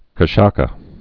(kə-shäsə)